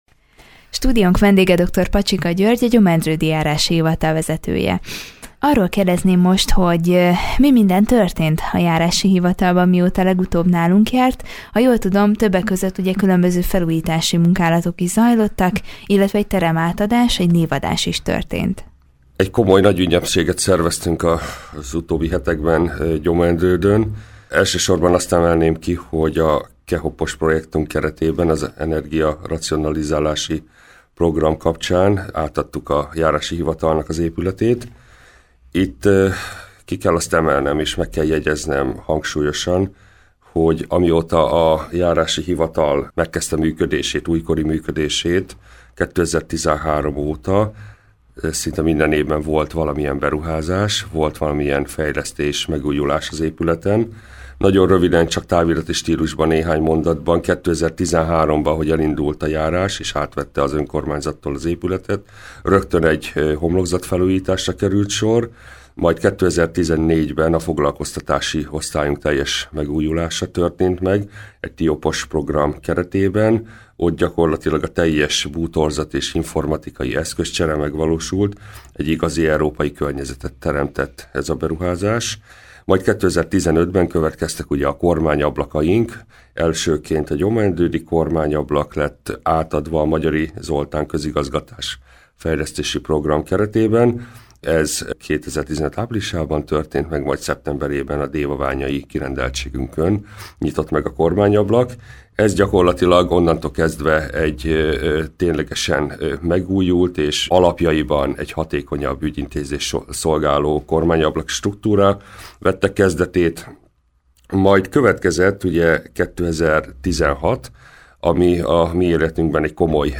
A rendezvények mellett fejlesztések is történtek a Gyomaendrődi járásban. Ezzel kapcsolatban beszélgetett tudósítónk Dr. Pacsika Györggyel, a Gyomaendrődi Járási Hivatal vezetőjével.